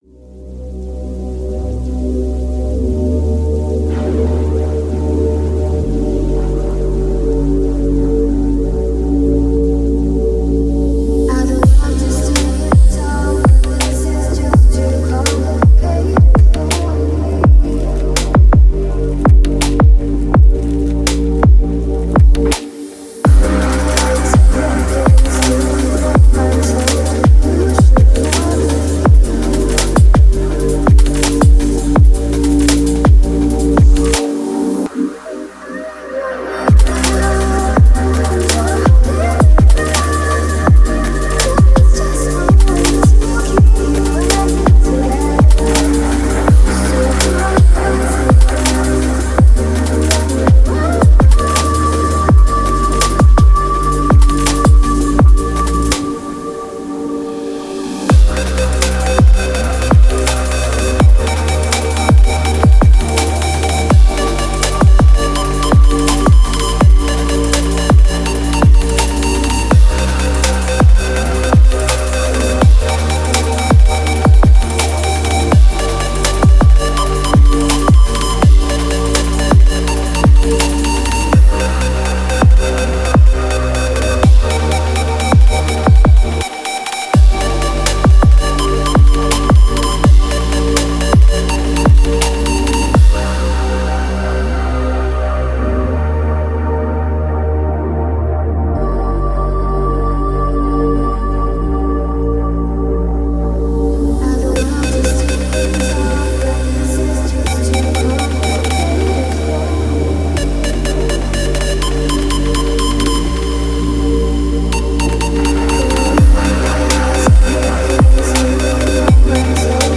музыка с басами